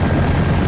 airutils_heli_snd.ogg